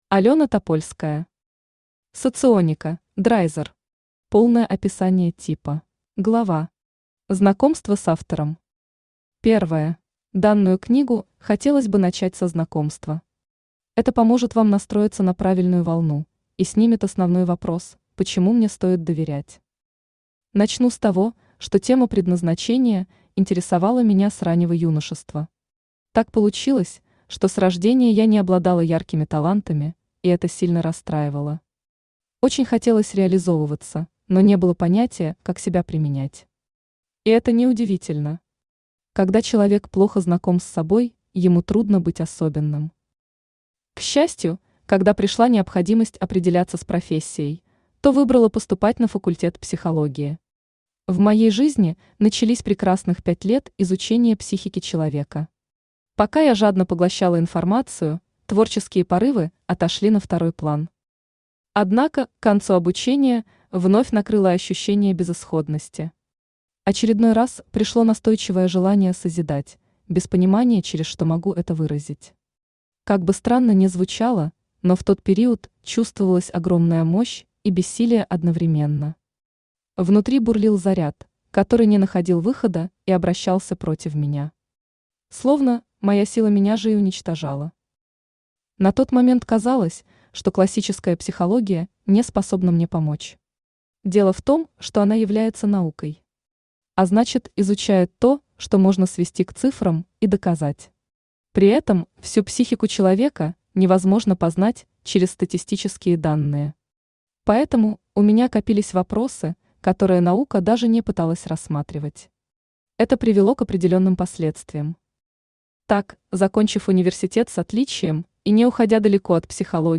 Аудиокнига Соционика: «Драйзер». Полное описание типа | Библиотека аудиокниг
Читает аудиокнигу Авточтец ЛитРес.